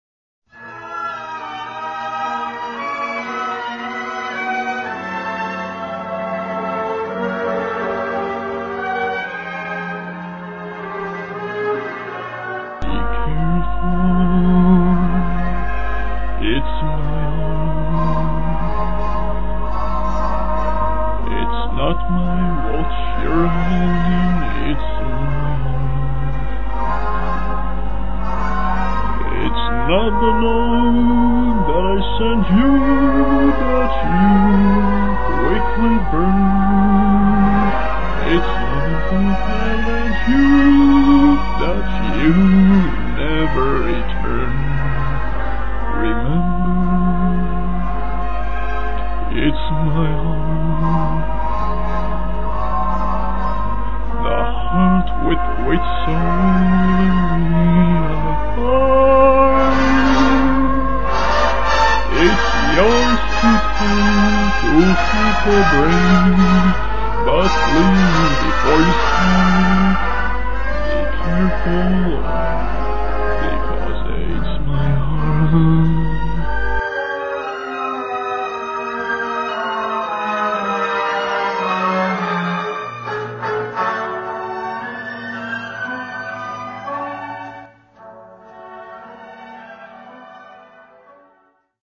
guy is singing with the ensemble.